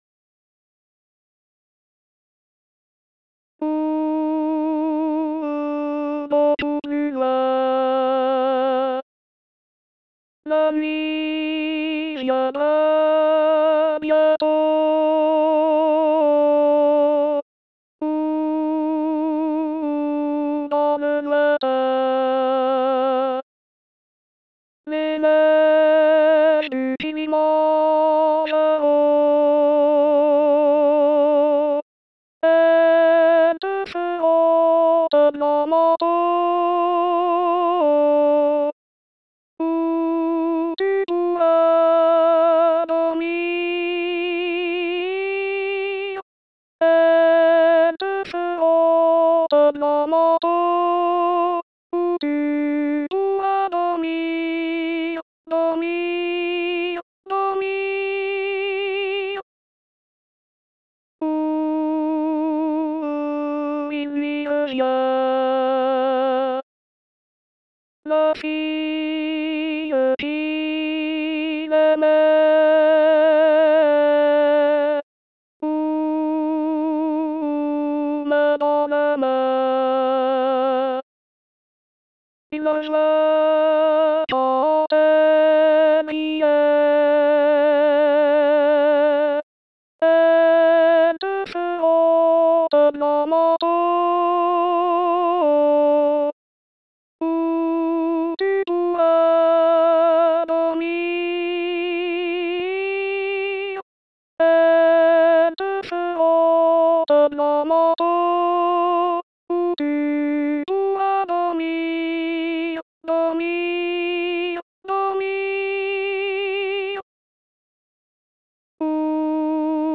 1) le chant complet à 3 voix, synthétiques comme d'habitude.
2-3-4) Les voix de soprane, alto, et hommes